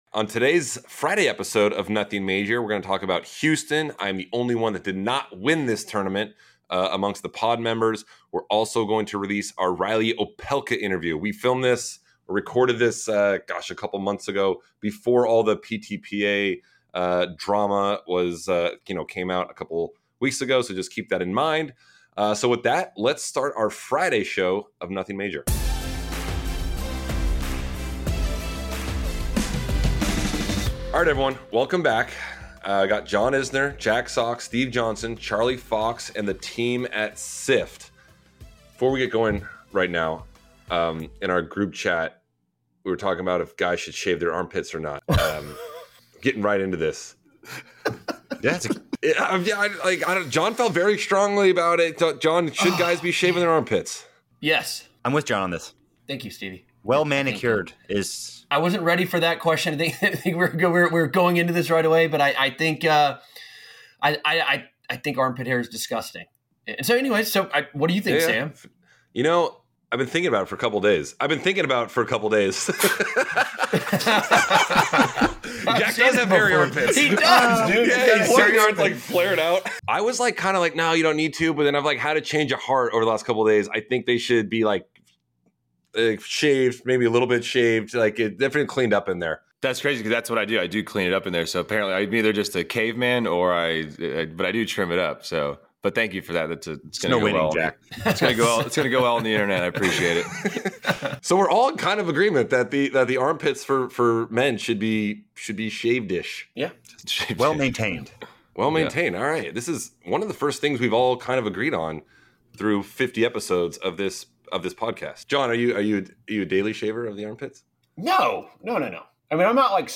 Interview with Reilly Opelka